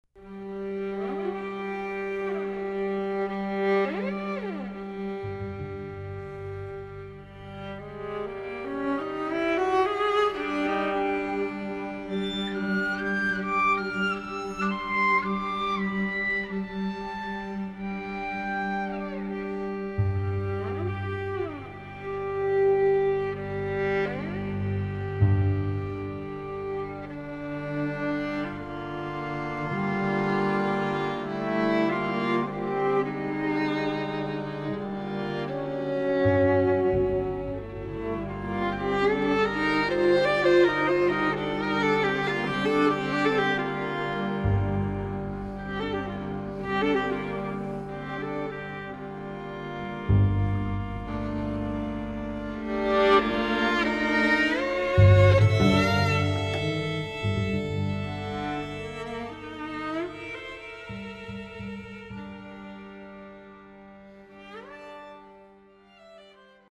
Please note: These samples are not of CD quality.
Quintet for Piano and Strings